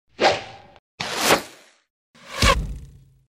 На этой странице вы найдете высококачественные записи звука выстрела из лука, свиста летящей стрелы и ее попадания в мишень.
Стрела пролетела и вонзилась